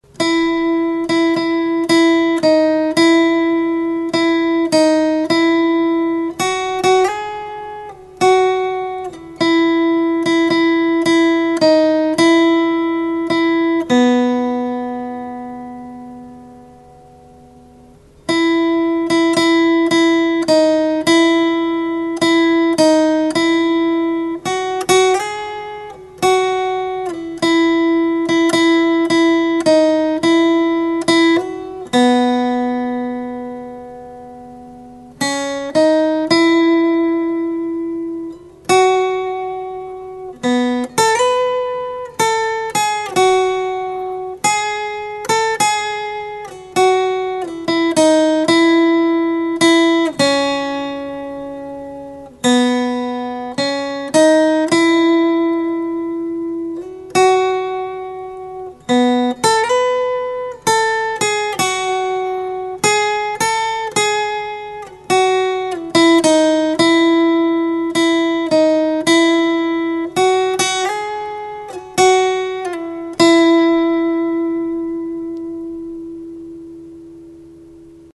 • вся мелодия играется на одной (второй) струне
Аудио мелодии на одной струне